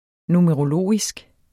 Udtale [ numəʁoˈloˀisg ]